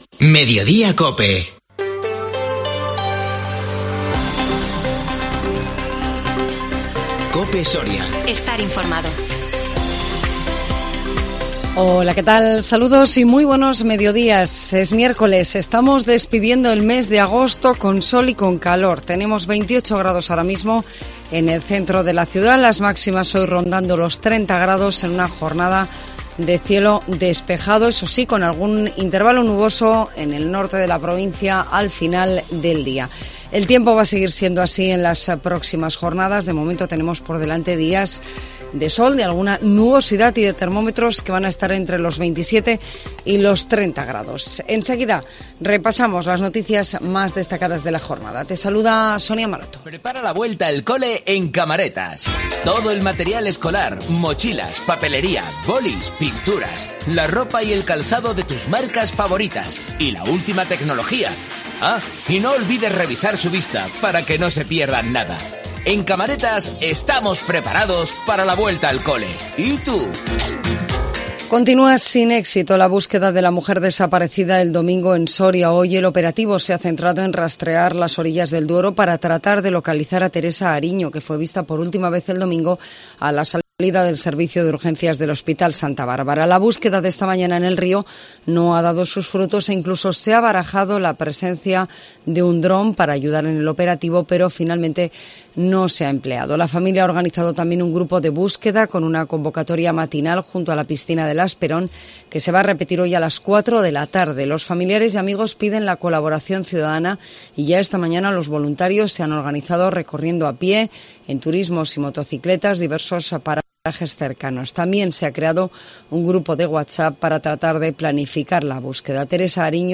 INFORMATIVO MEDIODÍA COPE SORIA 31 AGOSTO 2022